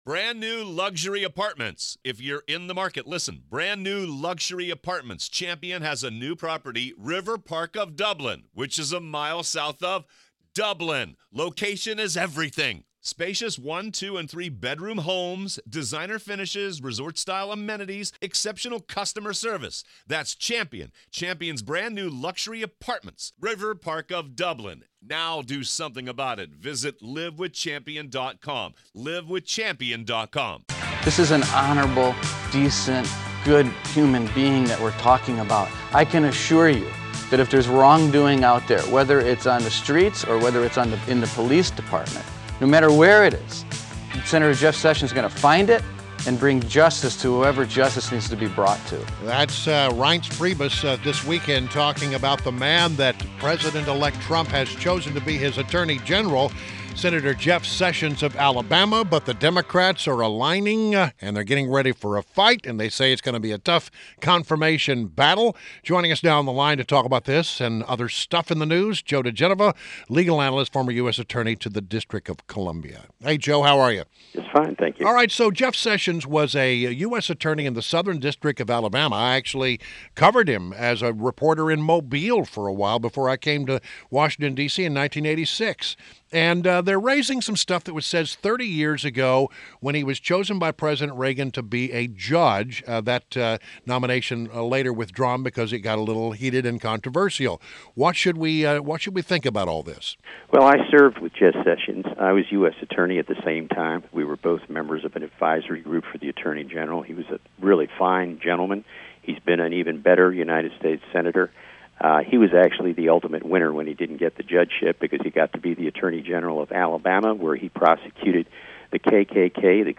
WMAL Interview - JOE DIGENOVA - 11.21.16